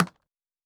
Click (24).wav